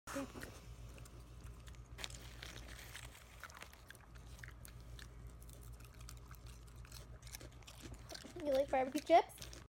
Just a mini pig snacking sound effects free download
Just a mini pig snacking on BBQ chips with her mom, in the house.